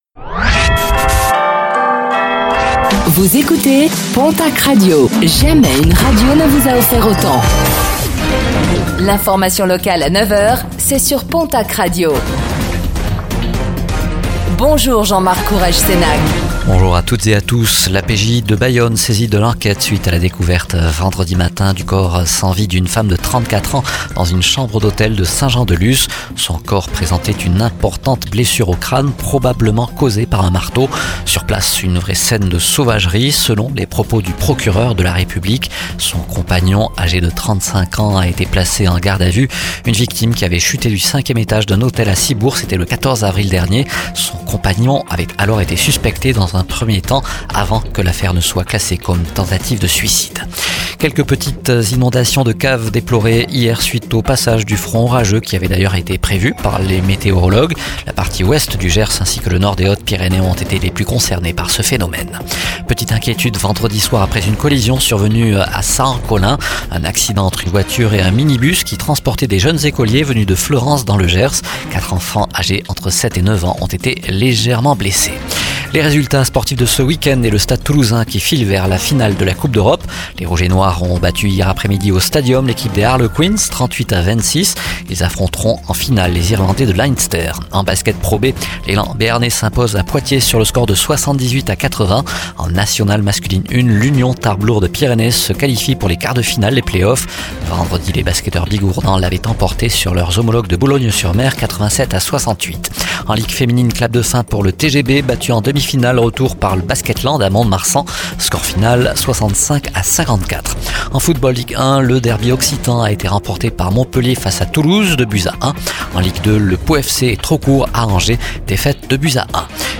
09:05 Écouter le podcast Télécharger le podcast Réécoutez le flash d'information locale de ce lundi 06 mai 2024